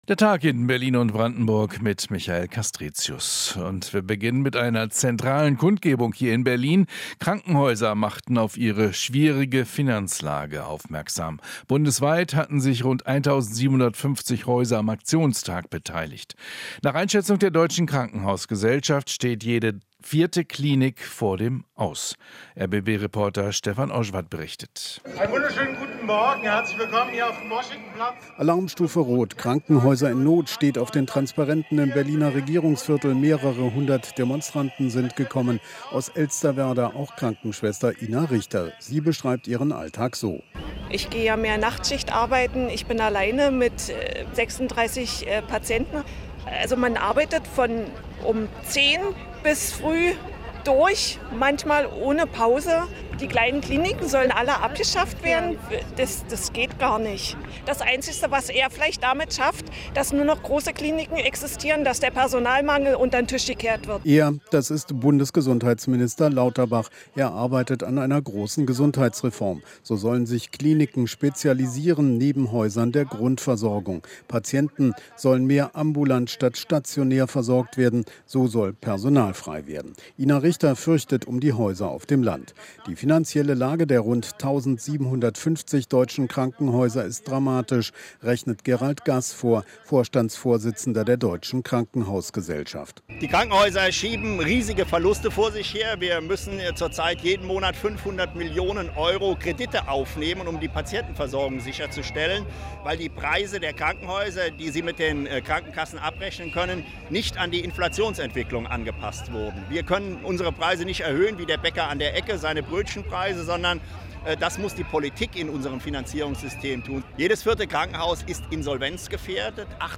Inforadio Nachrichten, 08.07.2023, 17:30 Uhr - 08.07.2023